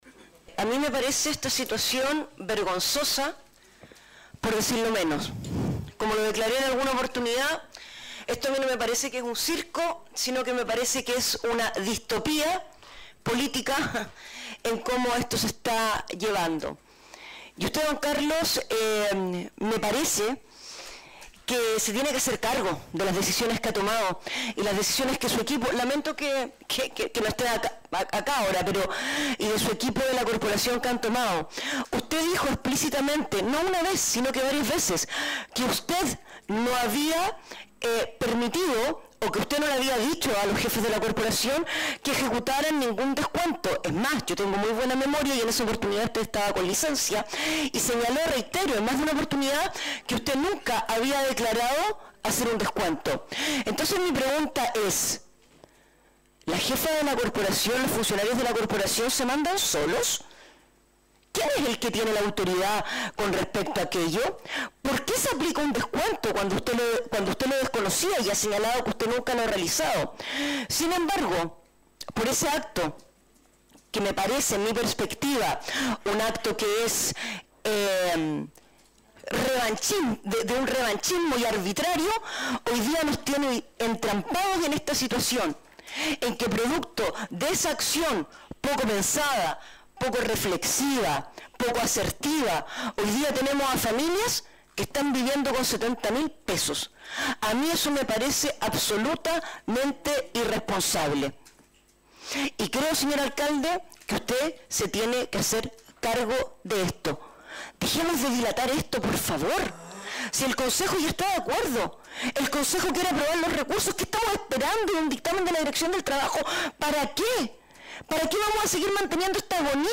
Mucha molestia e indignación llegó hasta la mesa del concejo municipal de Ancud la mañana del lunes por parte de personal docente.